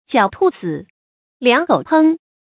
注音：ㄐㄧㄠˇ ㄊㄨˋ ㄙㄧˇ ，ㄌㄧㄤˊ ㄍㄡˇ ㄆㄥ
狡兔死，良狗烹的讀法